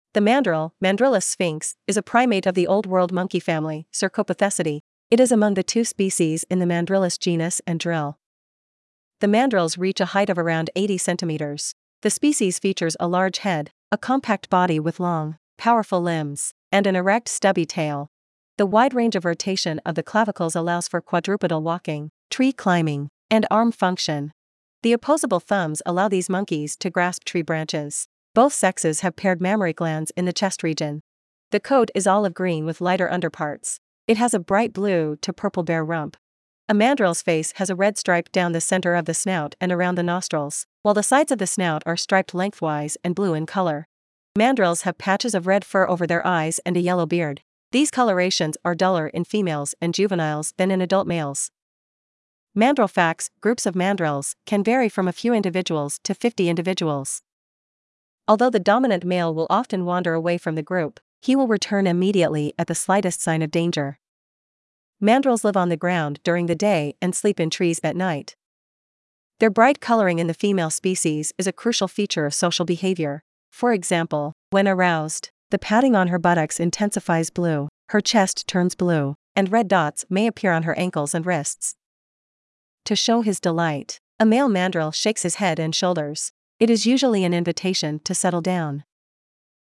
Mandrill
Mandrill.mp3